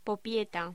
Locución: Popieta
voz
Cocina - Zona de preelaboración